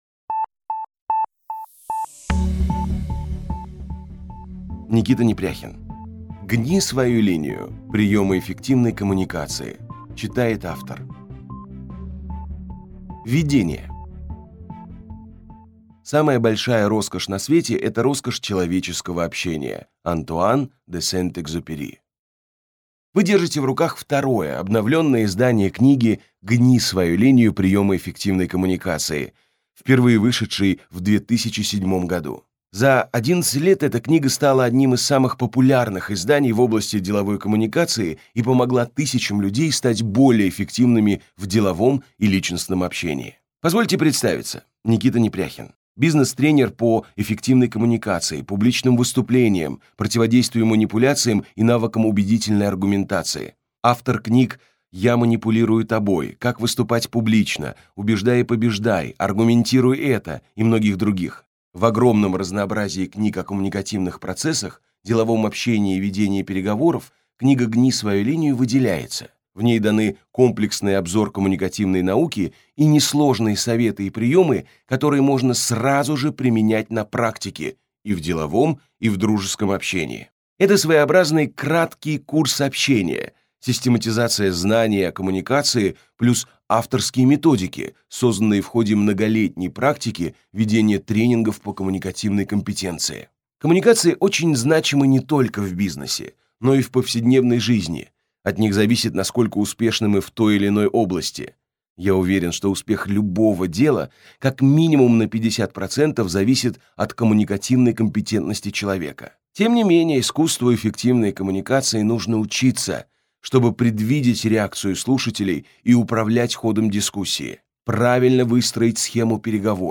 Аудиокнига Гни свою линию. Приемы эффективной коммуникации | Библиотека аудиокниг